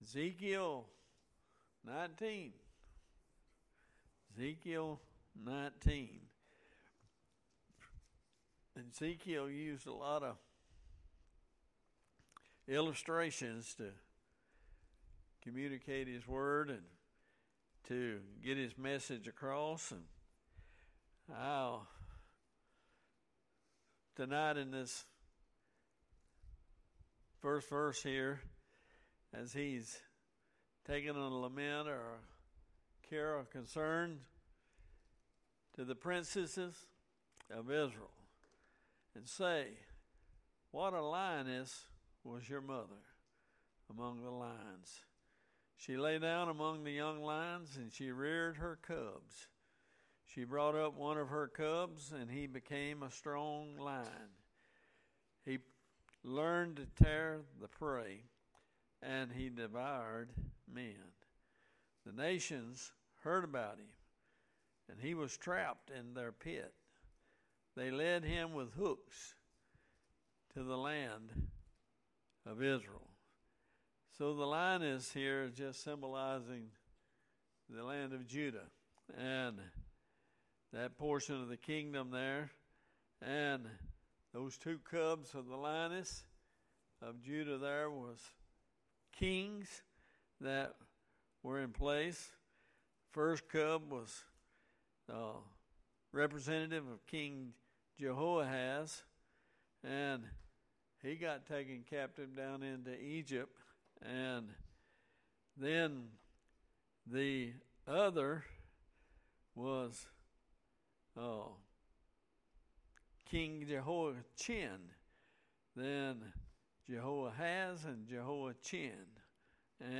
Sermons | Bexley Baptist Church
Bible Study